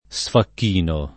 sfacchinare
sfacchino [ S fakk & no ]